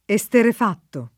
esterrefatto [eSterref#tto] (meno bene esterefatto [